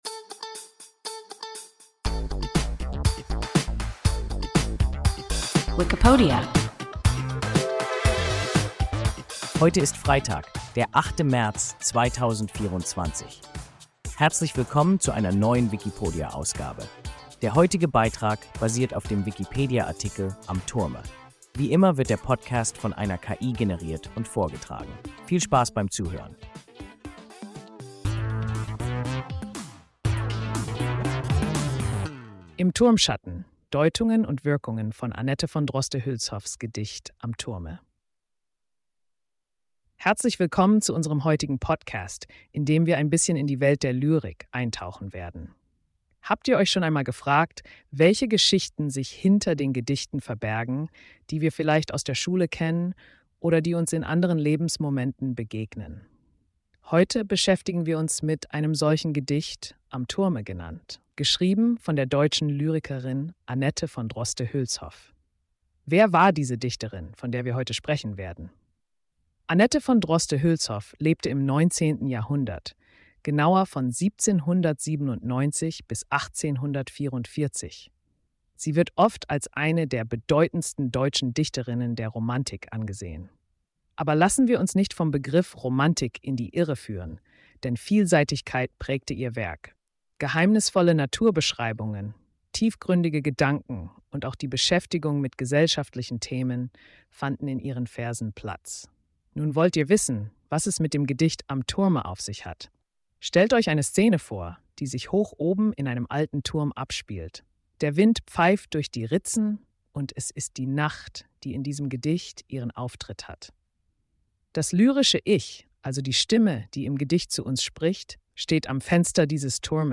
Am Turme – WIKIPODIA – ein KI Podcast